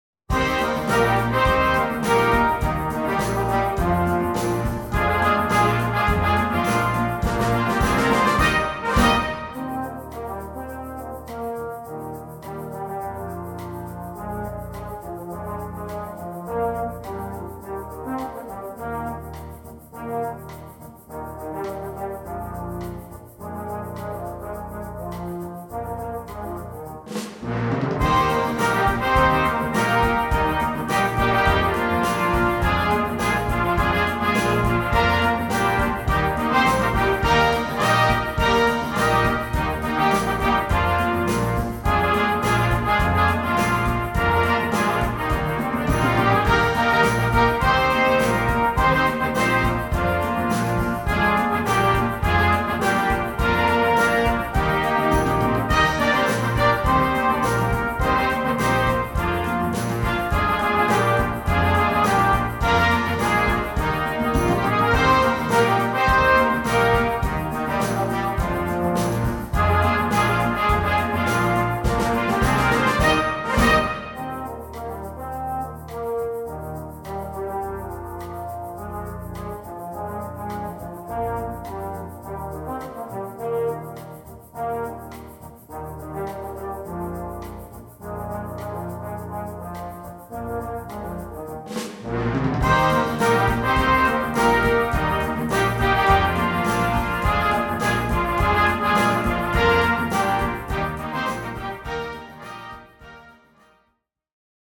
Gattung: Moderner Einzeltitel für Blasorchester
Besetzung: Blasorchester